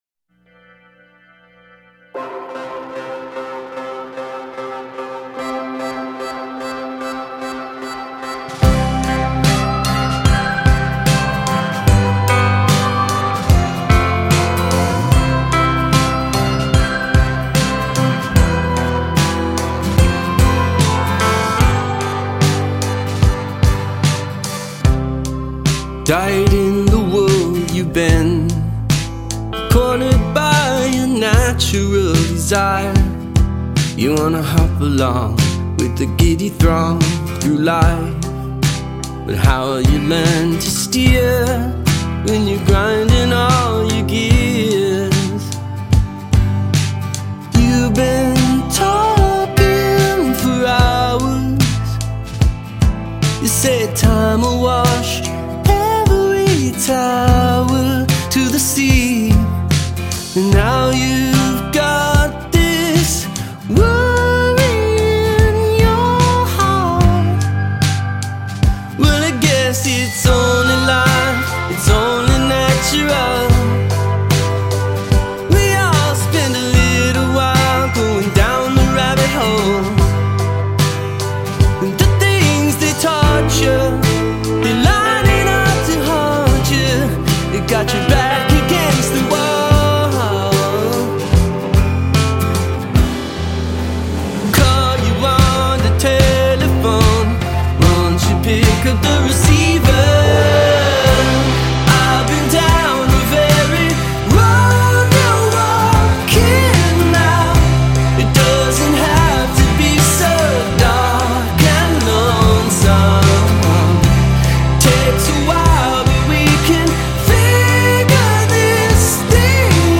indie pop
is awash with lush melody and fresh turn of phrase